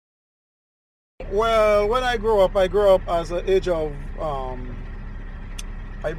Male. 40s. Taxi driver in St. Lucia in the Caribbean. He likes meeting new people.